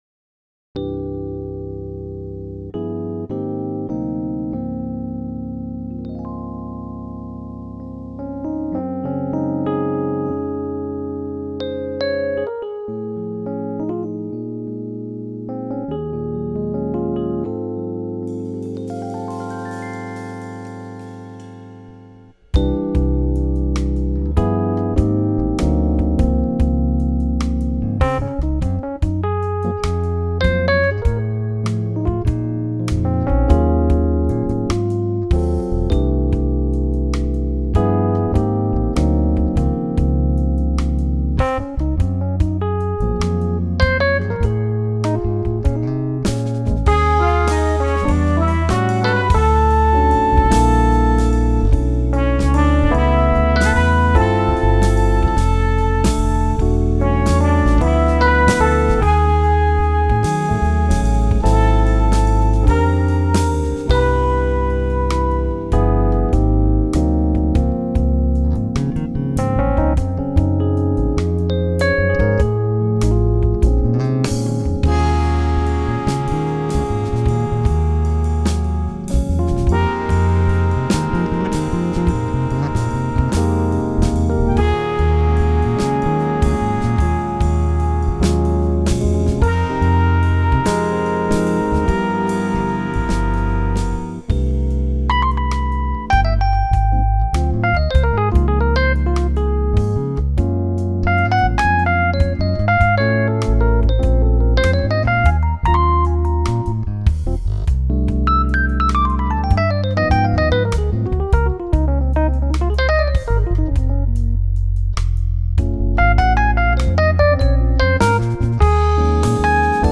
Audio Demos